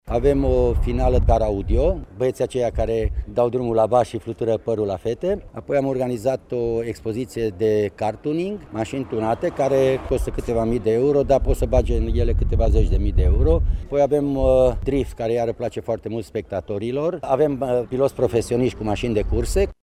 Un veteran al sportului cu motor